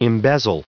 added pronounciation and merriam webster audio
1910_embezzle.ogg